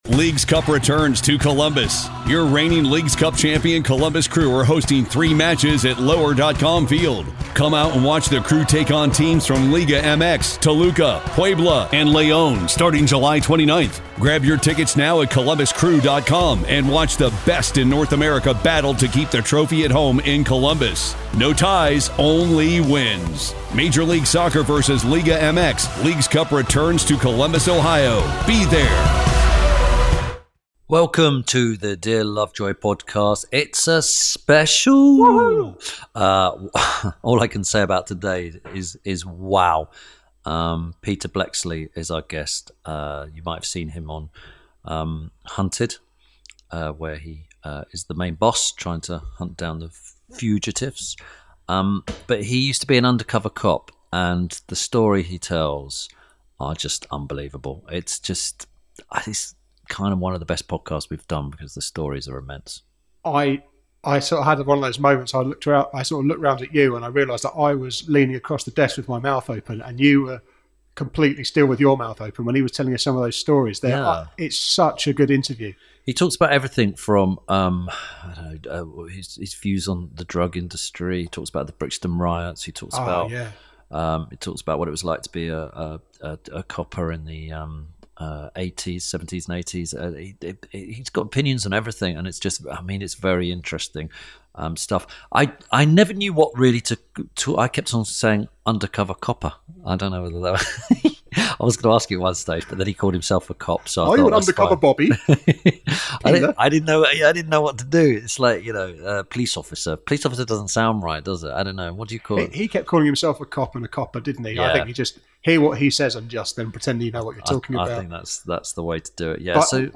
This week Tim Lovejoy talks to founder member of Scotland Yard’s undercover unit, author, and now TV presenter, Peter Bleksley. Tim and Peter discuss the Brixton riots, the subsequent life changing moment and having a contract on your life.